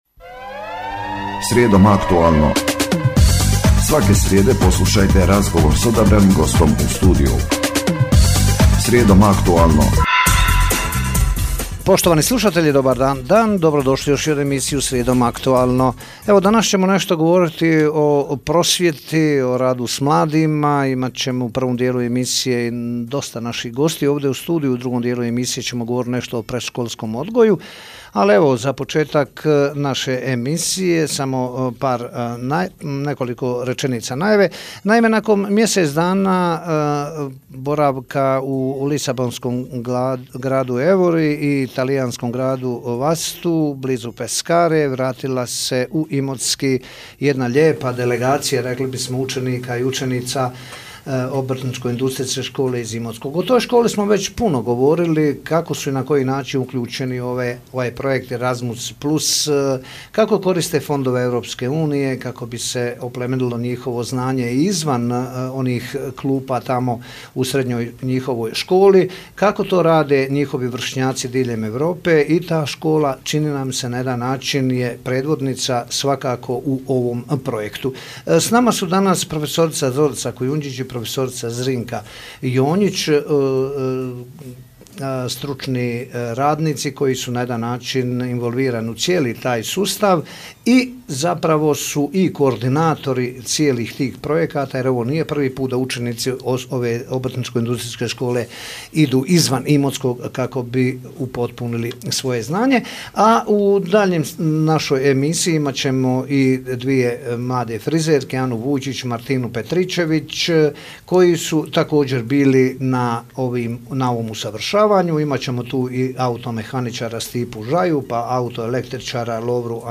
Po povratku sa mobilnosti bili smo gosti na Radio Imotskom, u emisiji "Srijedom aktualno", Radio Imotski je jedna od najslušanijih radio postaja u našem kraju.